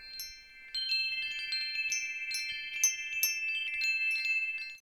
Weatherland Wind Chimes | Aluminum Wind Chime | Roland's Unique Gifts
Our Weatherland Chimes are hand-tuned and made of weather resistant materials for year round enjoyment.
#10A - $24.00 This light and airy chime is hand-tuned to the scale of G. As it's name suggests, Whisper of the Plains, resonates the freshness of a breeze on a clear summer day.